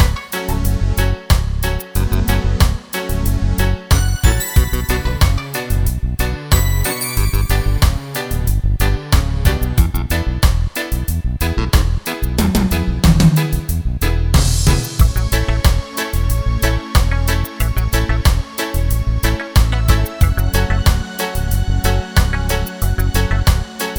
Reggae